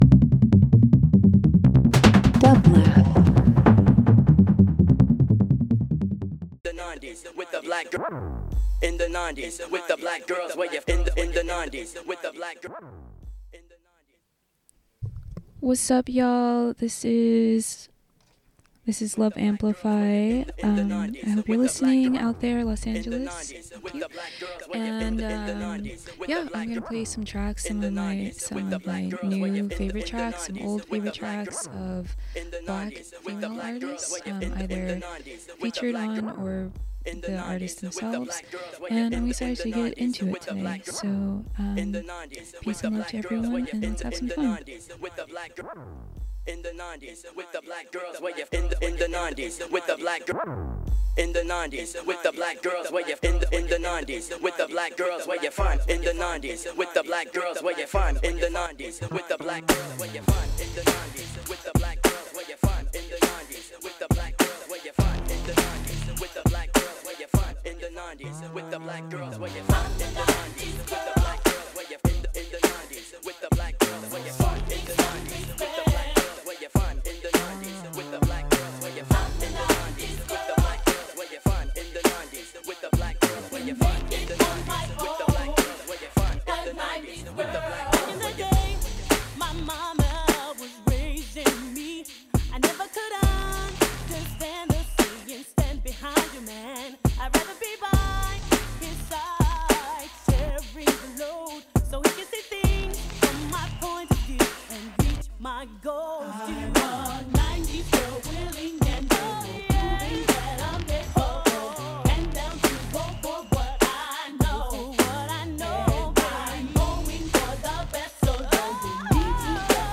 Avant-Pop Drum & Bass Electronic Trip Hop